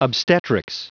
Prononciation du mot obstetrics en anglais (fichier audio)
Prononciation du mot : obstetrics